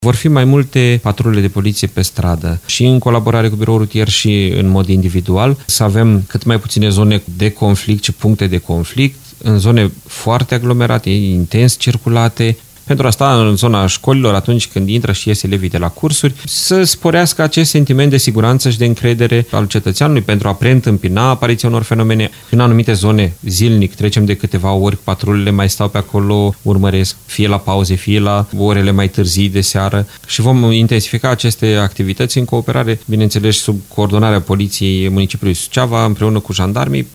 Șeful Poliției Locale OVIDIU DOROFTEI a declarat că cetățenii solicită o prezență mai consistentă în stradă pentru descurajarea faptelor antisociale.